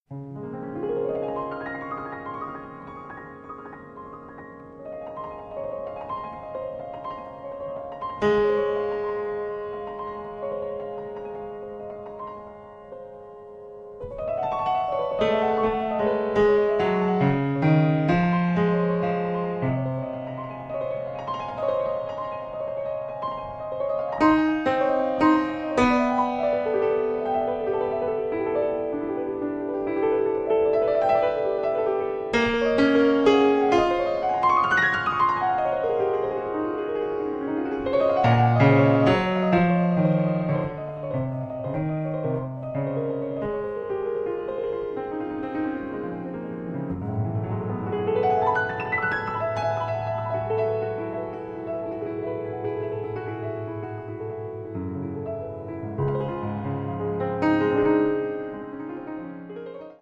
batteria
pianoforte
contrabbasso